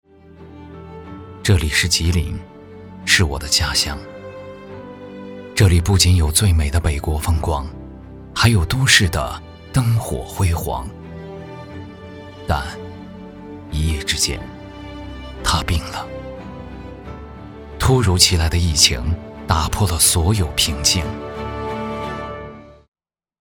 旁白-男11-走心长春加油！吉林加油！.mp3